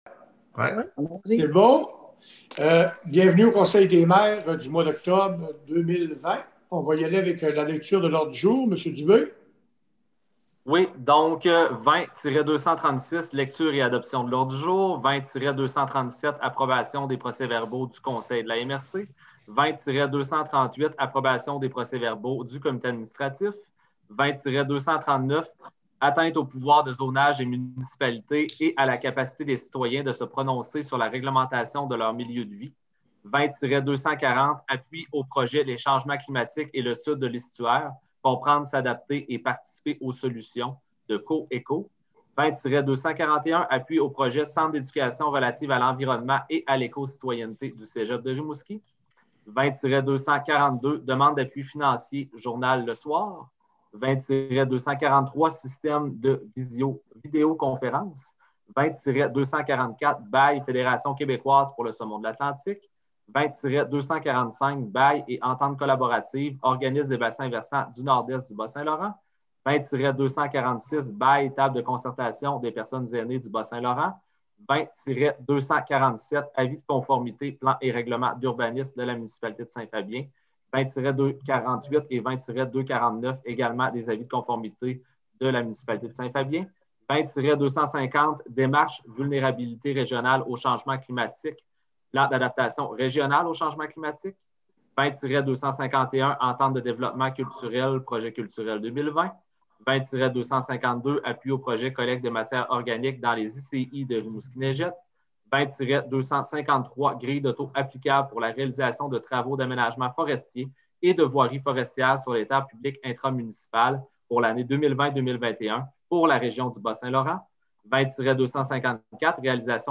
Voici l’enregistrement audio de la séance (virtuelle) du conseil de la MRC de Rimouski-Neigette du 14 octobre 2020.